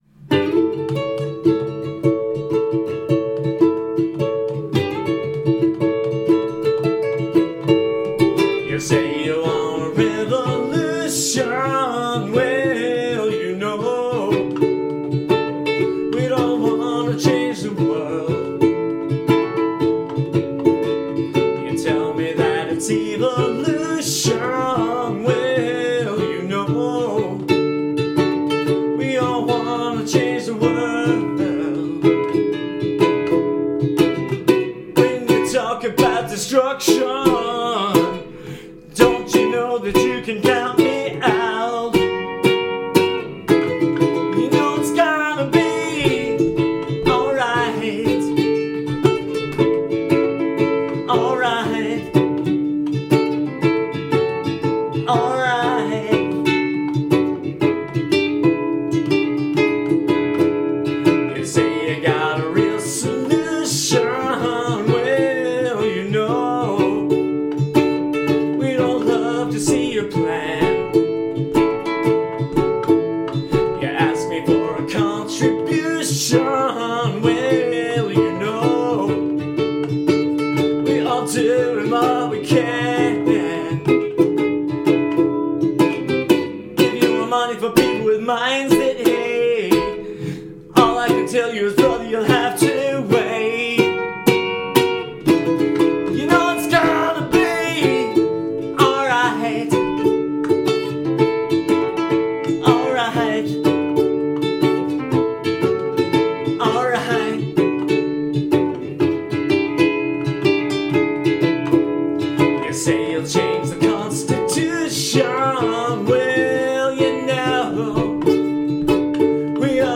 Latest Ukulele Practice Tunes